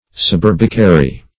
\Sub*ur"bi*ca*ry\, a. [LL. suburbicarius, equiv. to L.